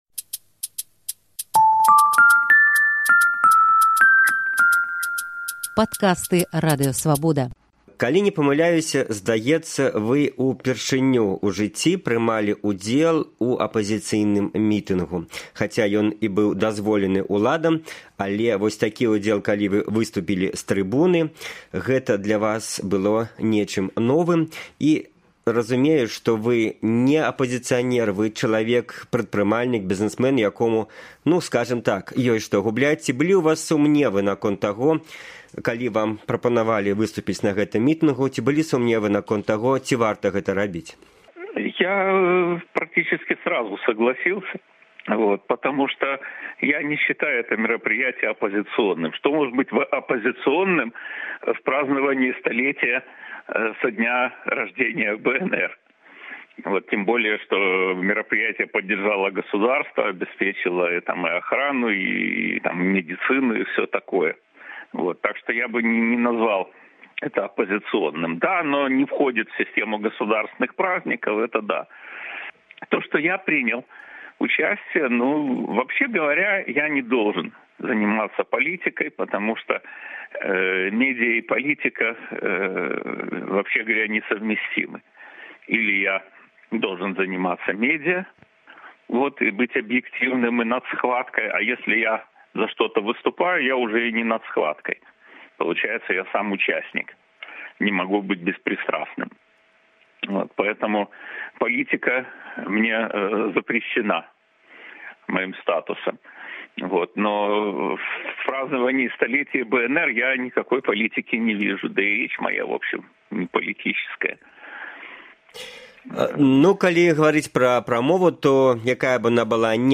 Госьць «Інтэрвію тыдня»